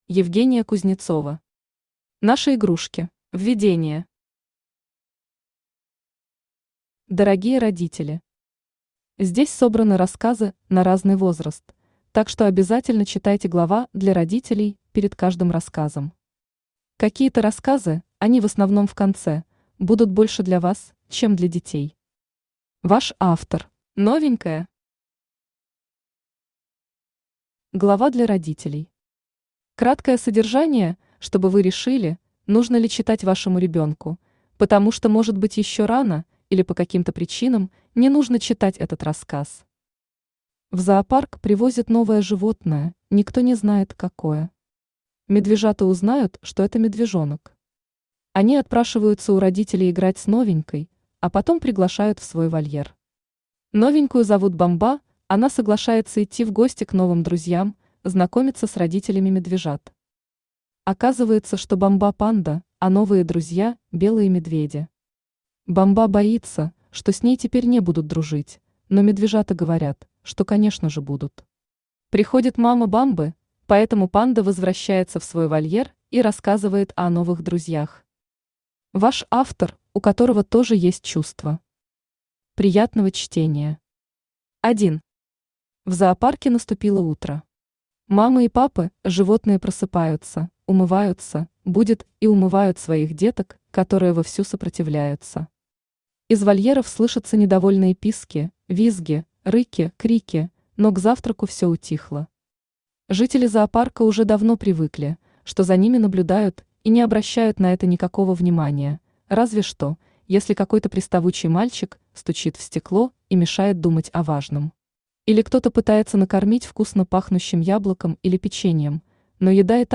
Аудиокнига Наши игрушки | Библиотека аудиокниг
Aудиокнига Наши игрушки Автор Евгения Кузнецова Читает аудиокнигу Авточтец ЛитРес.